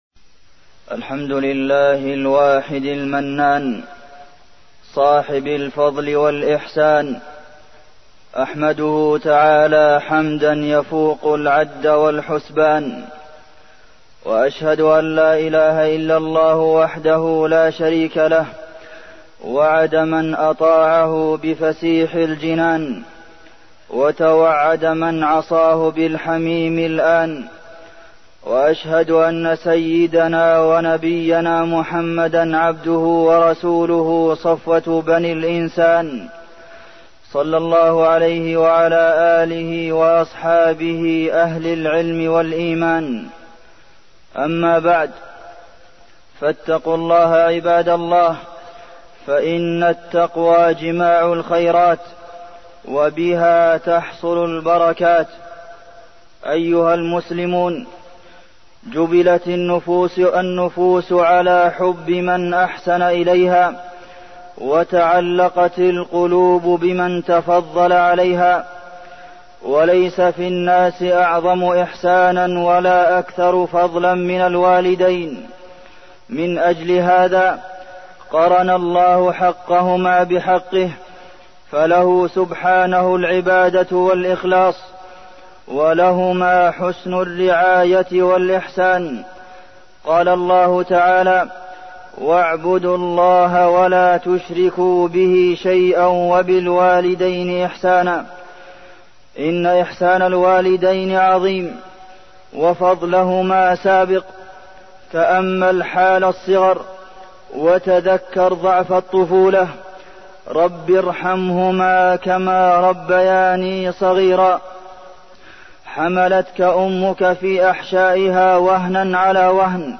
تاريخ النشر ٥ جمادى الآخرة ١٤١٩ المكان: المسجد النبوي الشيخ: فضيلة الشيخ د. عبدالمحسن بن محمد القاسم فضيلة الشيخ د. عبدالمحسن بن محمد القاسم بر الوالدين